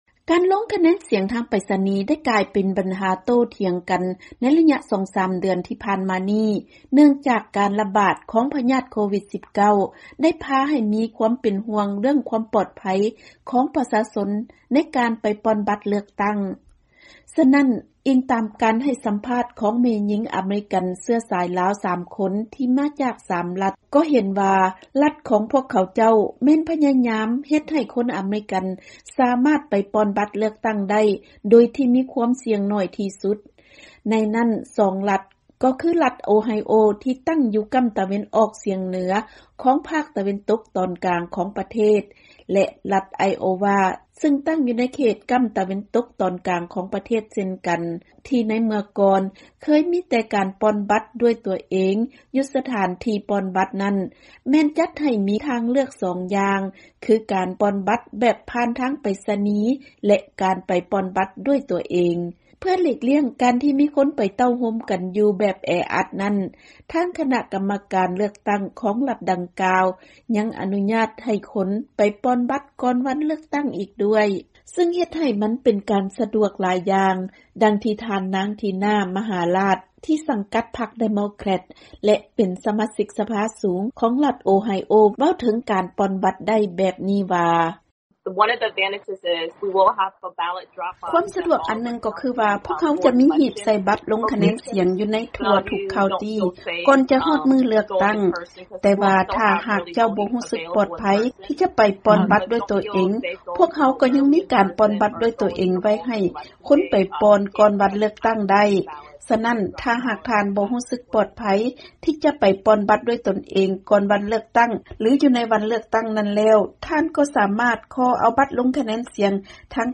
ເຊີນຟັງການສໍາພາດແມ່ຍິງລາວ 3 ຄົນກ່ຽວກັບການປ່ອນບັດເລືອກຕັ້ງປະທານາທິບໍດີ ສຫລ ຜ່ານທາງໄປສະນີ